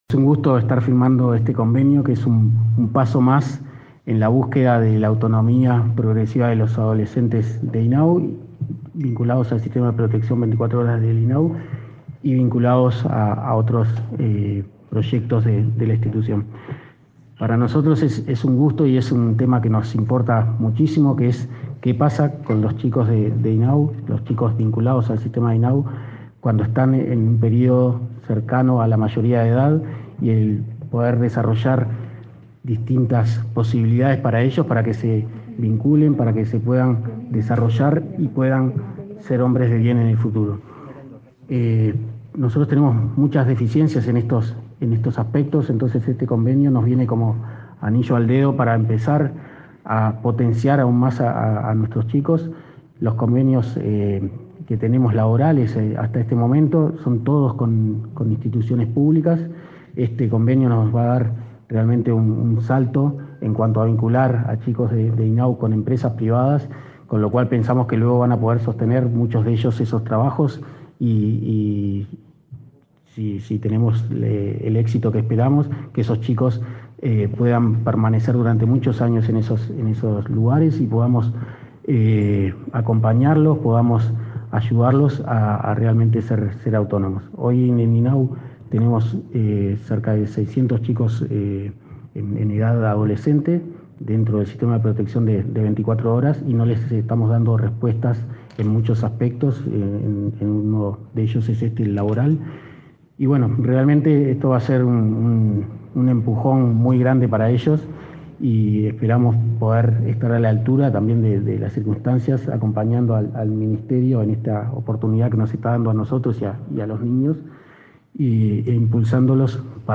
Palabras de autoridades del Ministerio de Trabajo y el INAU